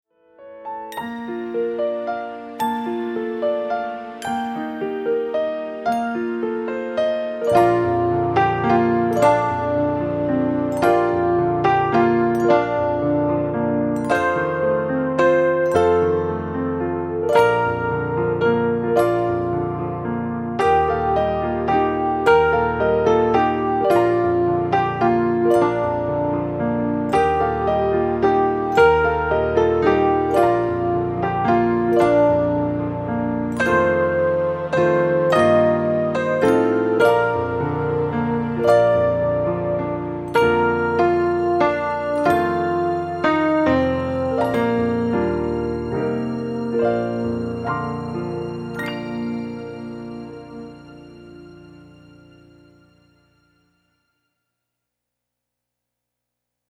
KIDS ONLY SING ALONG